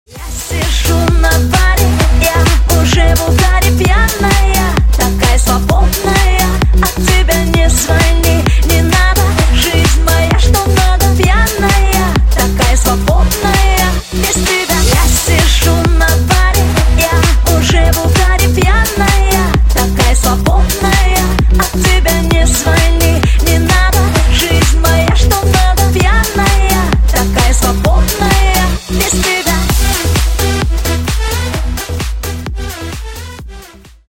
Скачать припев